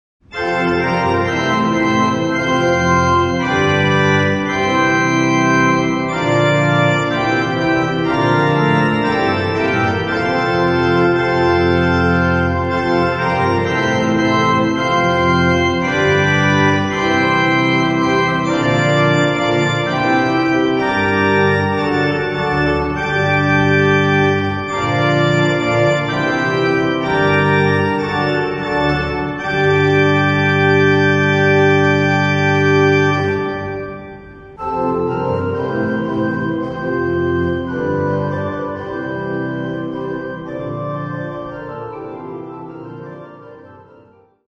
Organo "Amedeo Ramasco", Chiesa Parrocchiale di Crocemosso
Organo